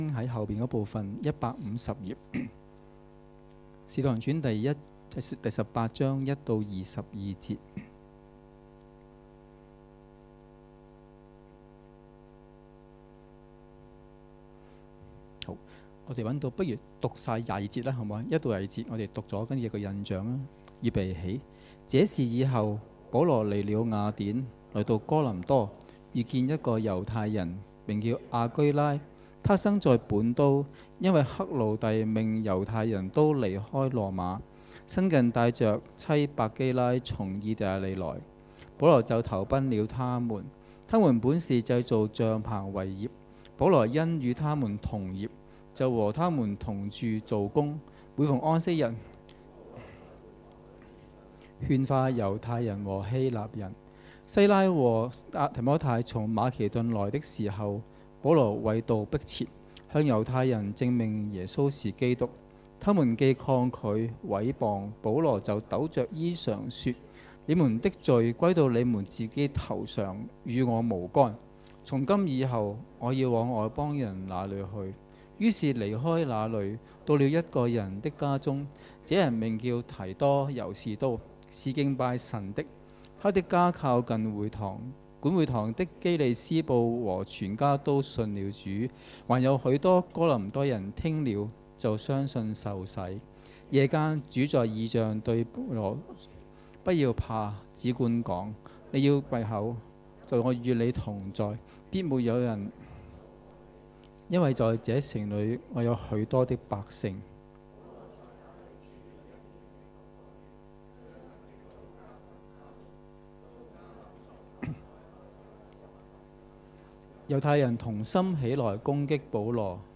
2018年10月6日及7日崇拜
講道 : 職業與召命